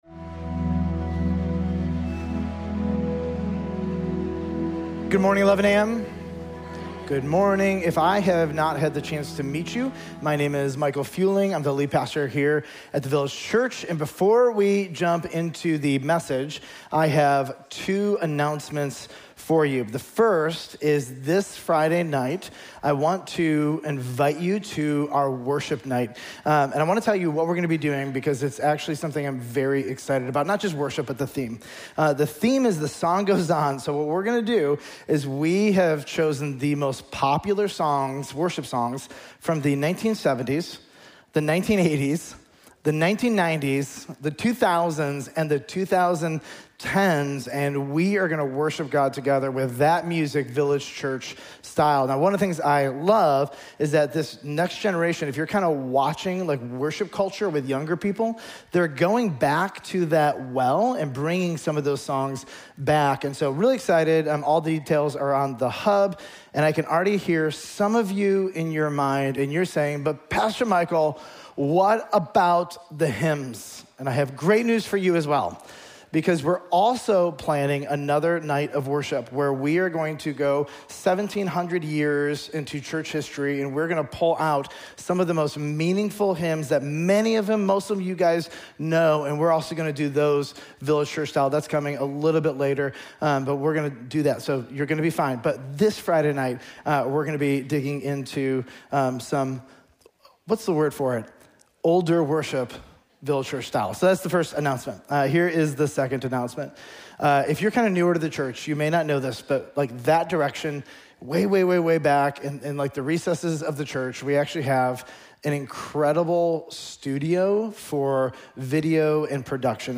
Sermons Jesus on Deuteronomy Pt. 3: You Have Heard It Said